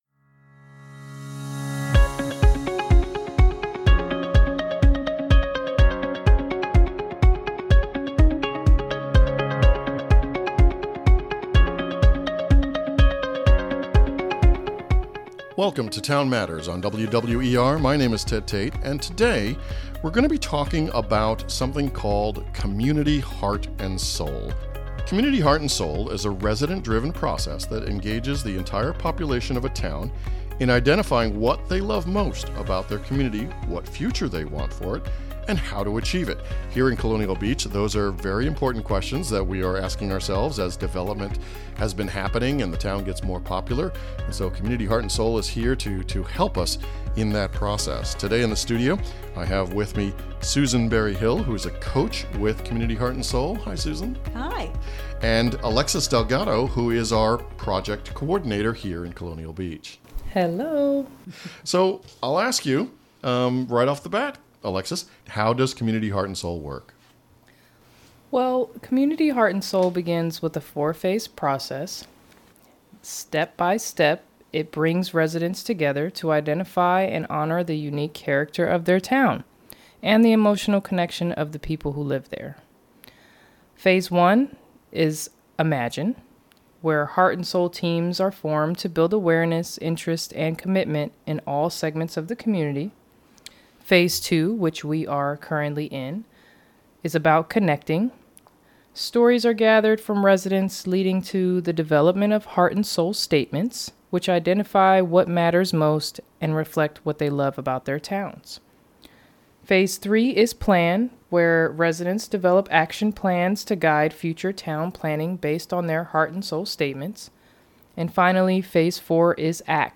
Our April edition of Town Matters is a discussion about the Community Heart and Soul initiative in Colonial Beach.